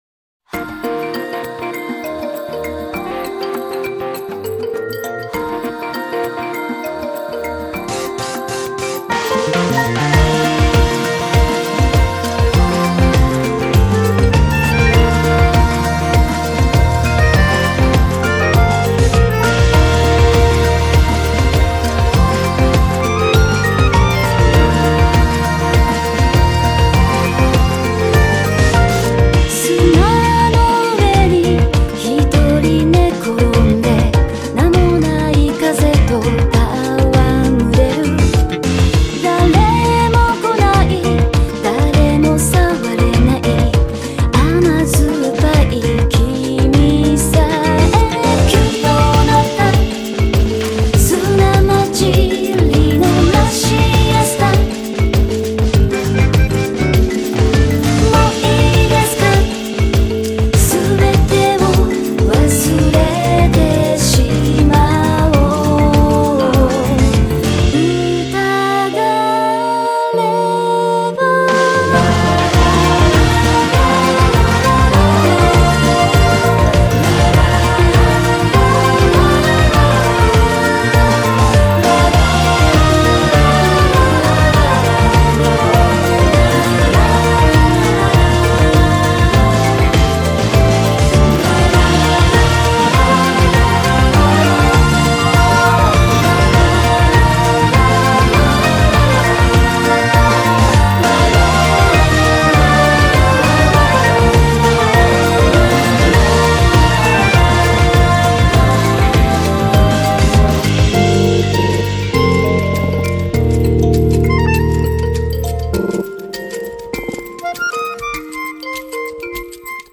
BPM100
Audio QualityPerfect (Low Quality)